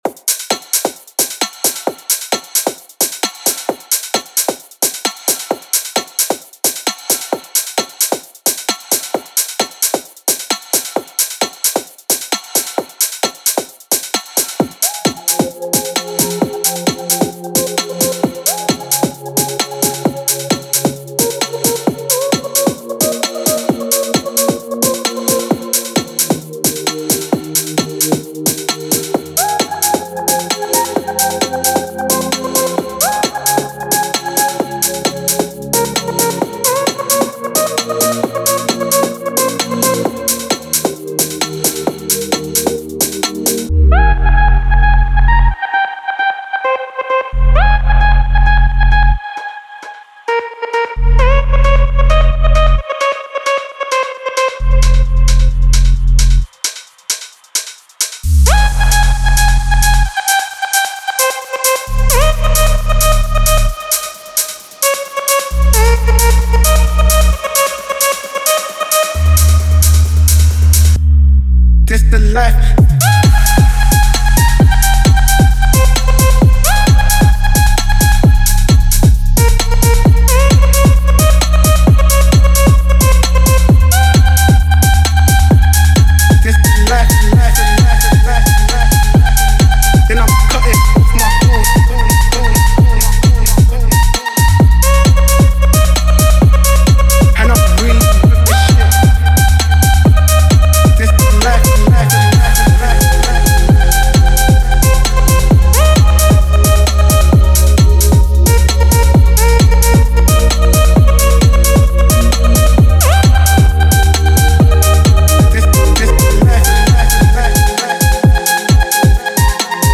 🎹 Key: F Minor
🥁 BPM: 132
💡 Genre: UK Garage
🎤 Vocal: Splice Sample